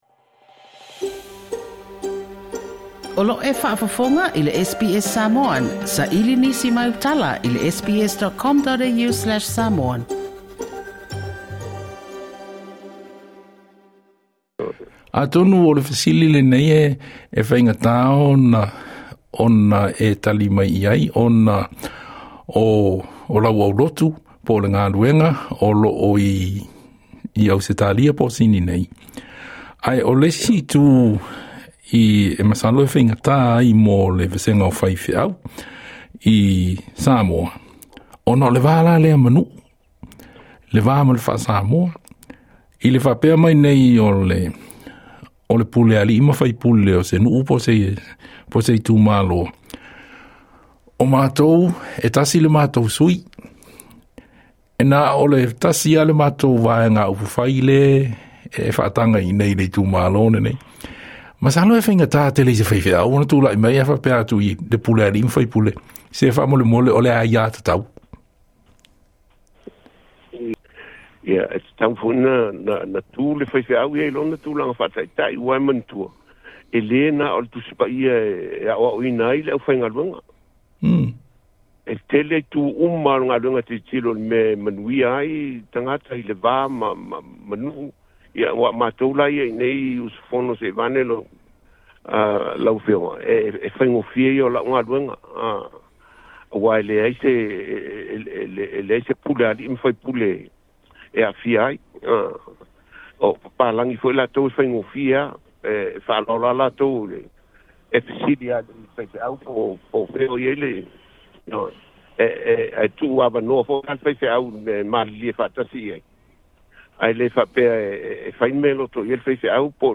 Na ia saunoa i le fa'atalanoaga ma le SBS Samoan, e iai le Justice Committee a Ekalesia So'ofa'atasi i Ausetalia e fa'aalia ni manatu ma fautuaga i mata'upu e oia ai le filemu ma le nonofo fealofani i le atunu'u. O lona popolega o le le sa'oloto o le fa'atinoga o le filifiliga sa'oloto a tagata Samoa ona o le malosi o pulega a ali'i ma faipule o nu'u ma alaalafaga.